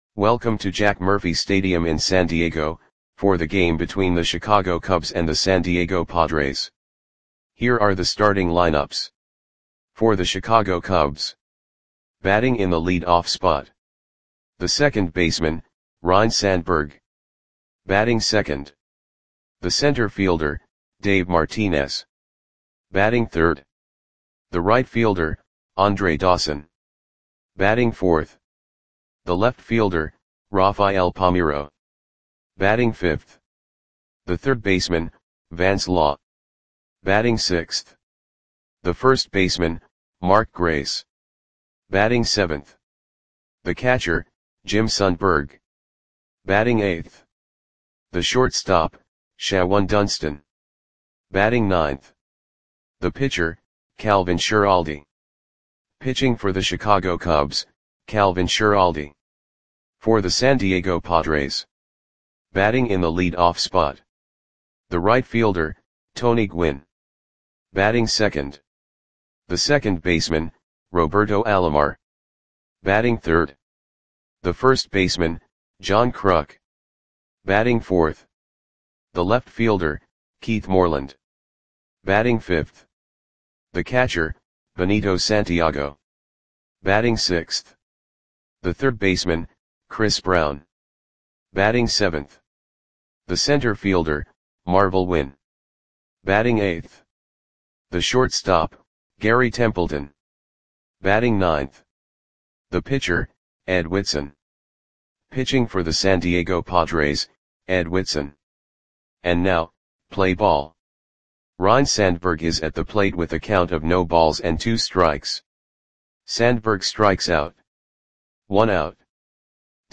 Audio Play-by-Play for San Diego Padres on May 3, 1988
Click the button below to listen to the audio play-by-play.